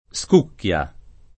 scucchia
scucchia [ S k 2 kk L a ]